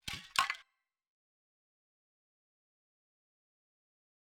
spray_additionals_001.wav